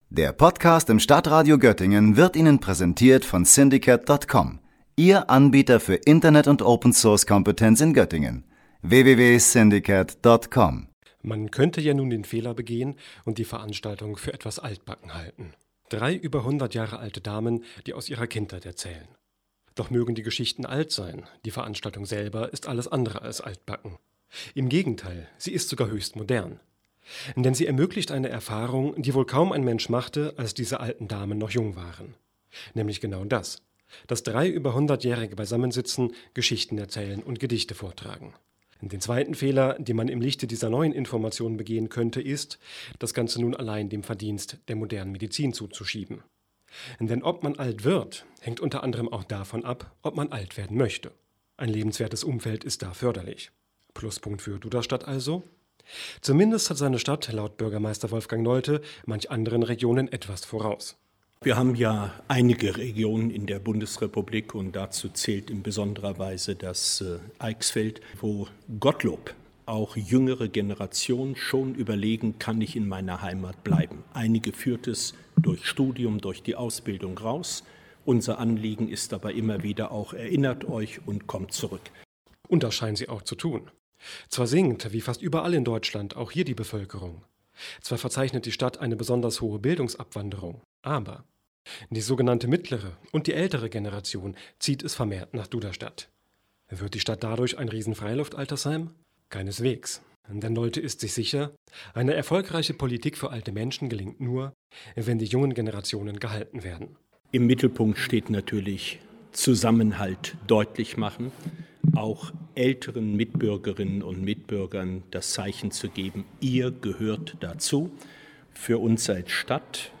Einmal im Jahr tagt der Rat bei Kaffee und Kuchen im Rathaus.